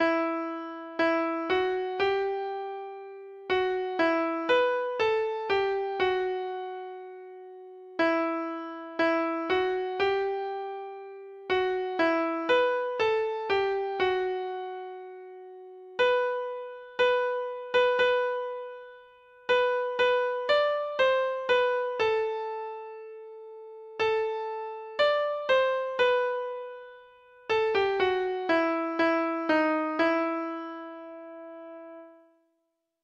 Free Sheet music for Treble Clef Instrument
Traditional (View more Traditional Treble Clef Instrument Music)